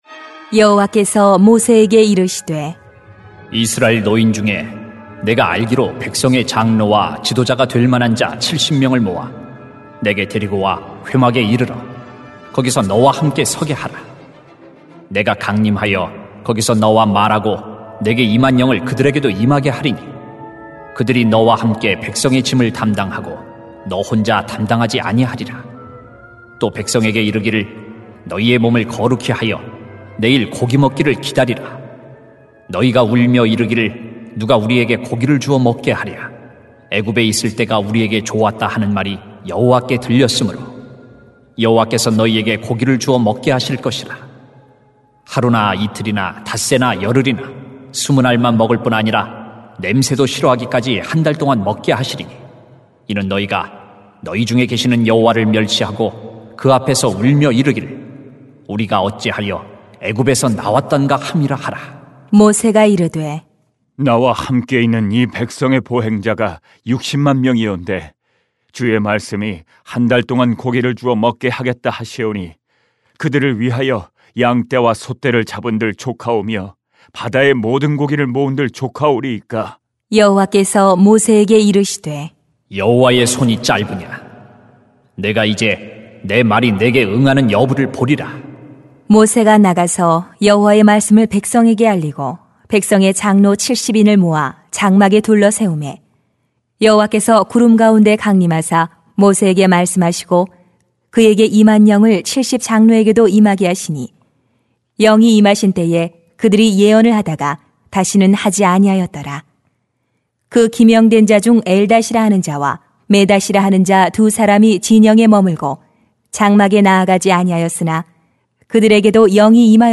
[민 11:16-36] 거룩히 해야 합니다 > 새벽기도회 | 전주제자교회